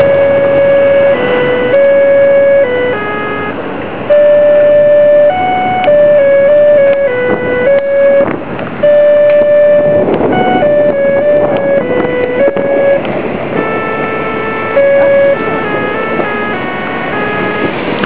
さて、大阪府は寝屋川市内で名古屋電機の警交仕規２１号の通りゃんせを収録致しました（我がHPでも掲載予定ですが）。
しかし、スピーカーが大阪に良くある細長い円筒形のものではなく、名古屋電機標準(?)の短い円筒形のスピーカーでした。
｢視覚障害者用交通信号付加装置｣
フルコーラスではない点が残念ではありますが。
因みにこの日は風が非常に強かったため、雑音が入っております。
音程は京三の通りゃんせに似ているようですが、音質は全くのオリジナルのようですね。
こちらはいかにも古い感じに聞こえてしまいます(^^;)。
風が強くても結構綺麗に収録できていると思います(^^)。
結構音が短めに切れているようにも思えます。
私の収録はデジカメでやっておりますので、棒等の先っぽにつけて収録、と言うのは出来ないのです(しかも20秒だけ；)。